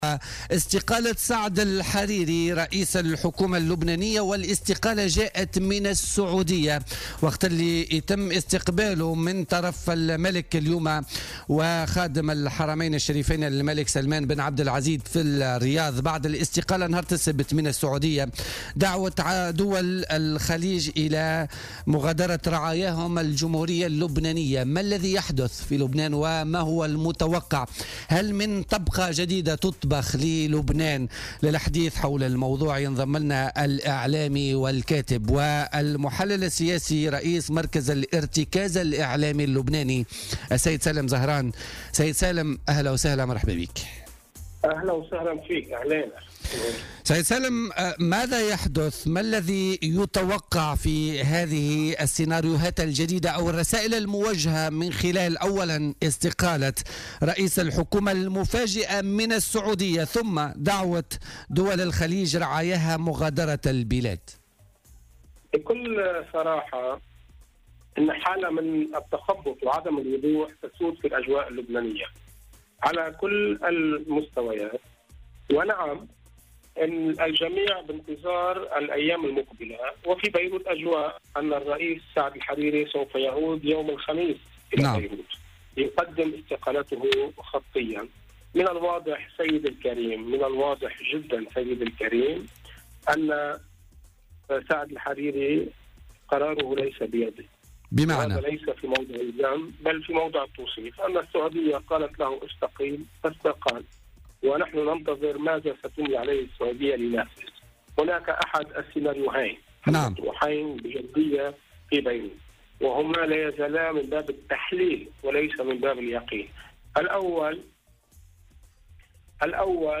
ورجّح في اتصال هاتفي من لبنان بـ "بوليتيكا" على "الجوهرة اف أم" اليوم الاثنين، أن يكون قرار الحريري ليس بيده وانه استجاب لطلب السعودية في واقع الأمر، وذلك في قراءته لتطور الأوضاع في لبنان، بحسب رأيه.